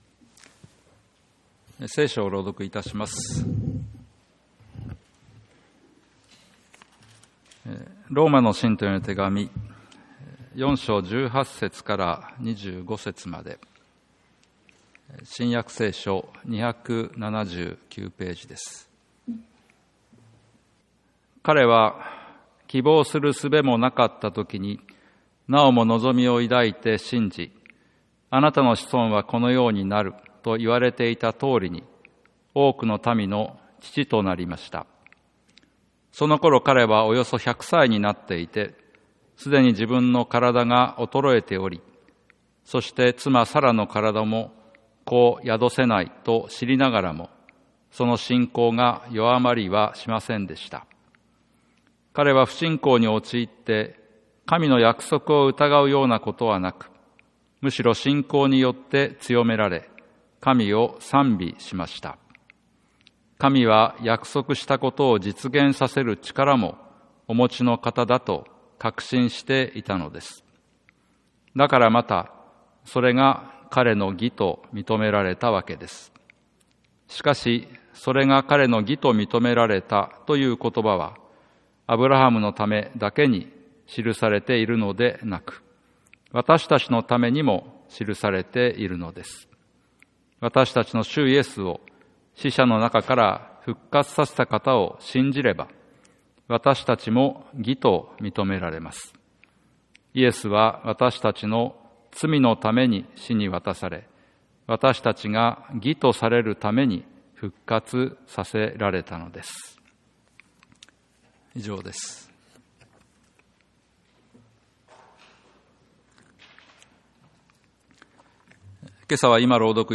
2024年3月31日 朝の礼拝